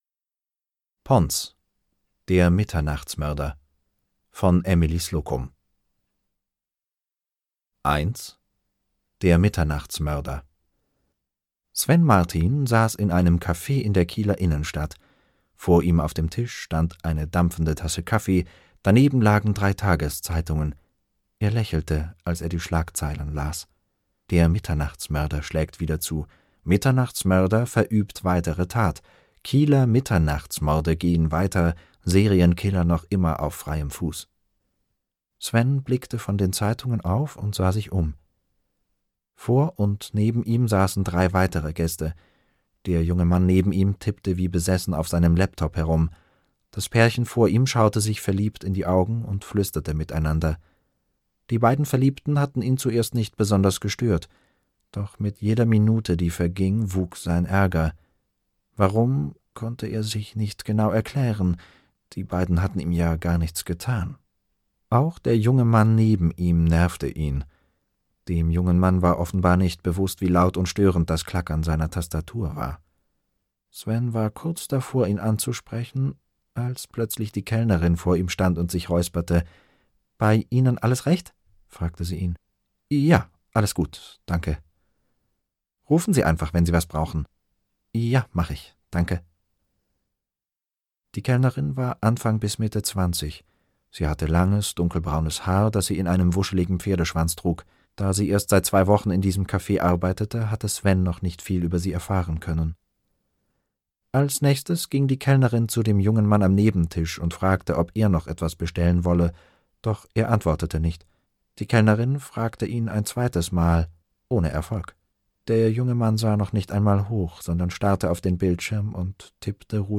PONS Hörkrimi Deutsch: Der Mitternachtsmörder - Emily Slocum - Hörbuch